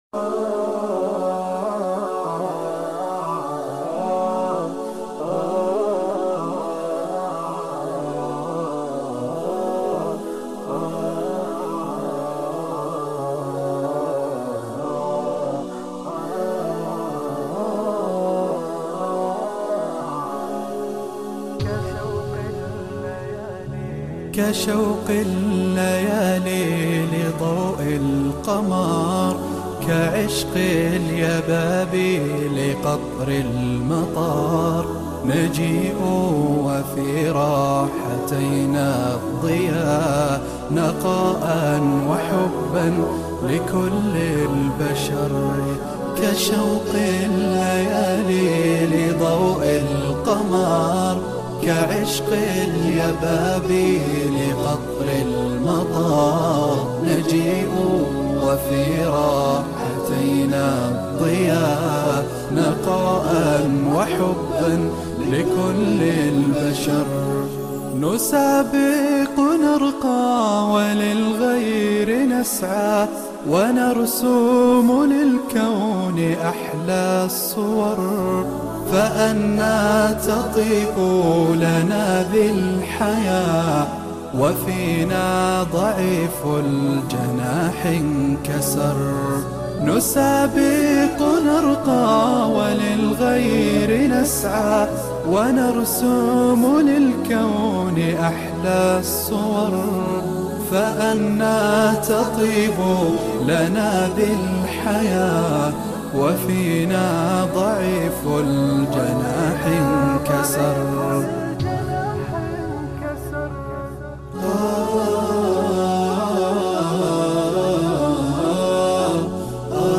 انشوده